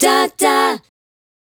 Dah Dah 152-F#.wav